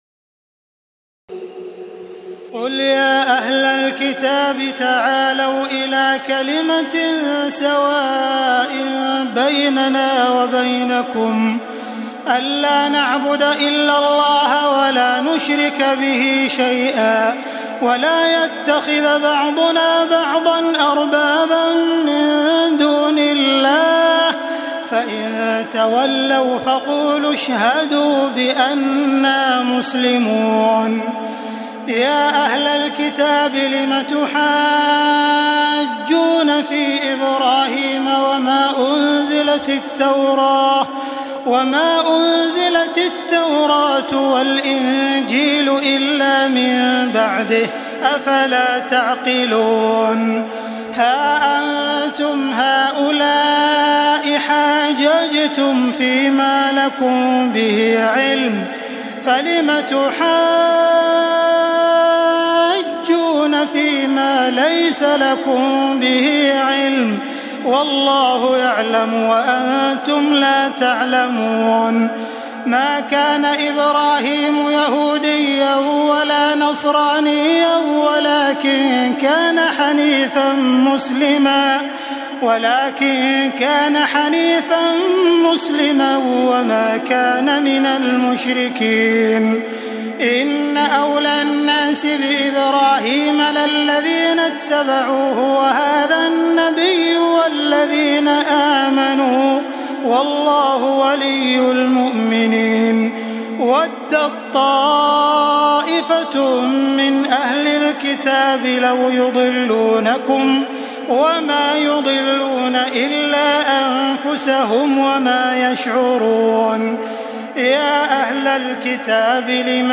تراويح الليلة الثالثة رمضان 1432هـ من سورة آل عمران (64-151) Taraweeh 3st night Ramadan 1432 H from Surah Aal-i-Imraan > تراويح الحرم المكي عام 1432 🕋 > التراويح - تلاوات الحرمين